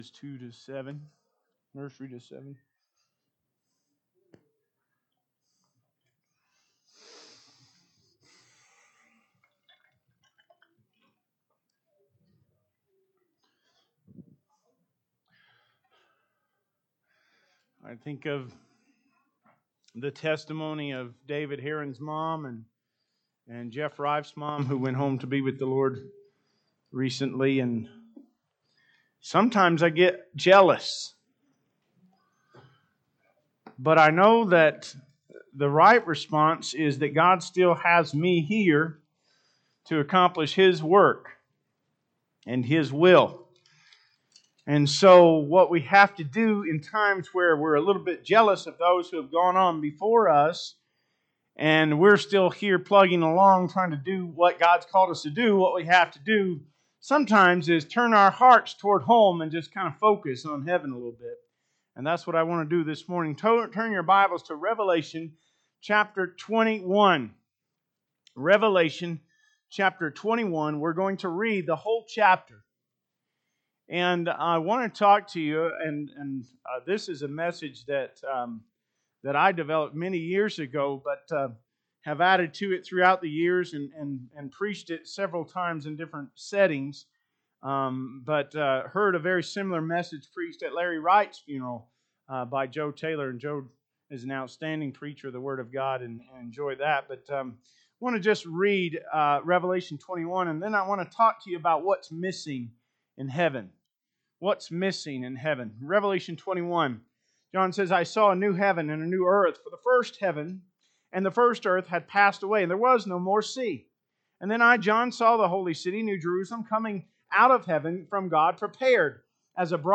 Sermons | Smith Valley Baptist Church